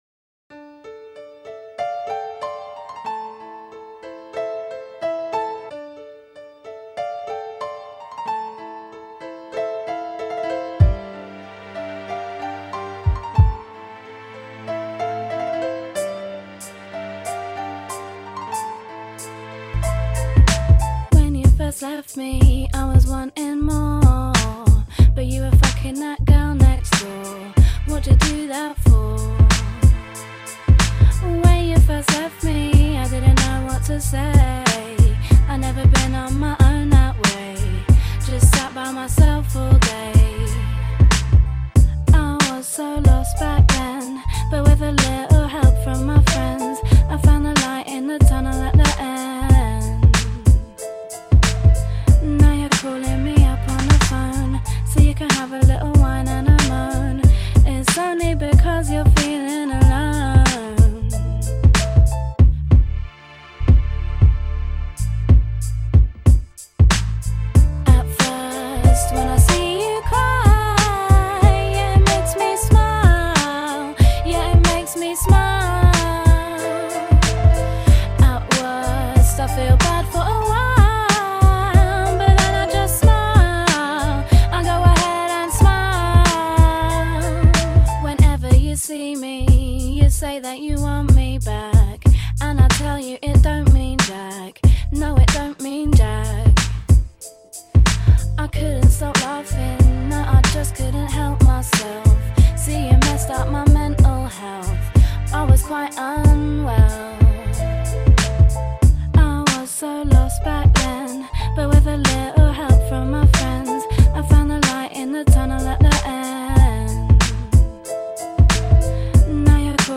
Bootleg
Acapella
Instrumentale